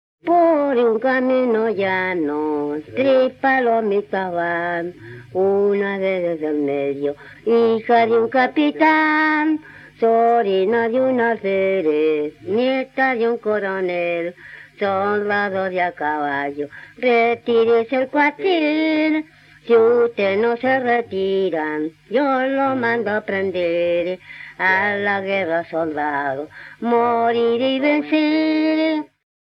Romance en forma de canción que trata el tema tradicional de "las hijas del capitán".
Fue recopilado por Gabriela Pizarro.
Música tradicional
Folklore